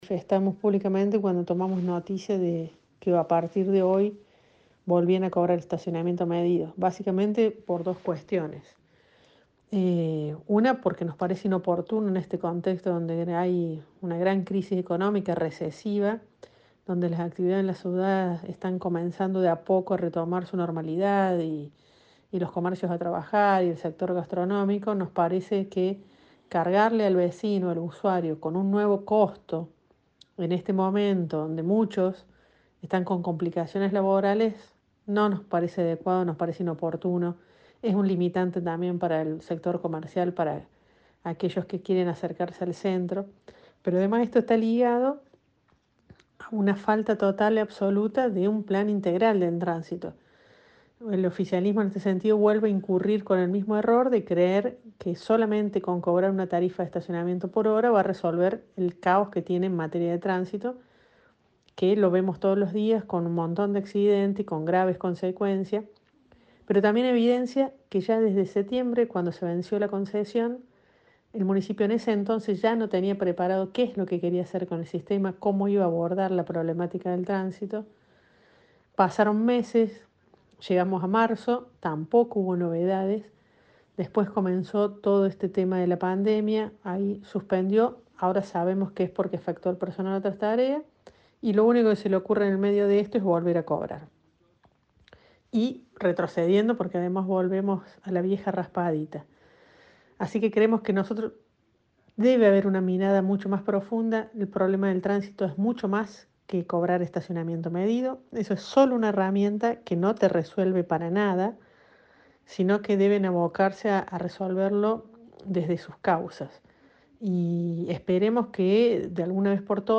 La concejal, Karina Bruno, habló con Cadena 3 Villa María sobre la postura planteada por su bloque.